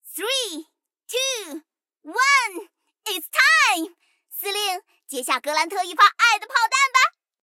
M3格兰特誓约语音.OGG